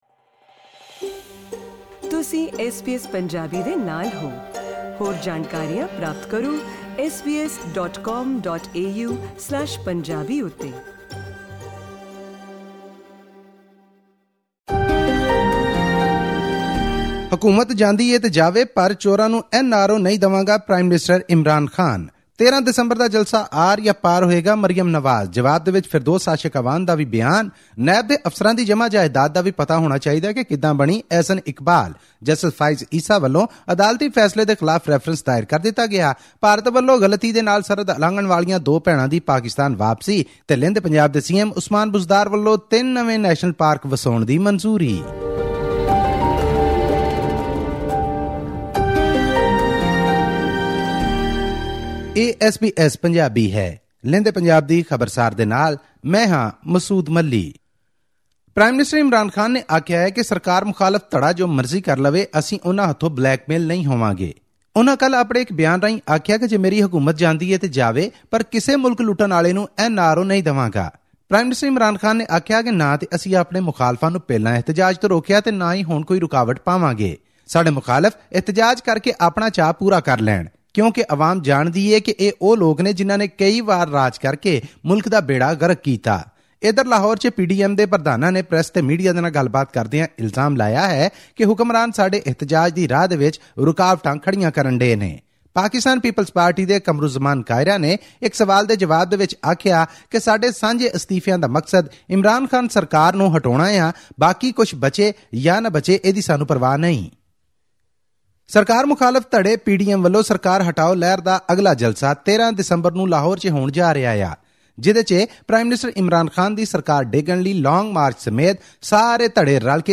In our weekly news report from Pakistan’s Punjab province, Pakistan Muslim League-Nawaz's (PML-N) Vice President Maryam Nawaz launched yet another scathing attack on Prime Minister Imran Khan as part of the Opposition's anti-government ‘action plan’ to build momentum against Khan.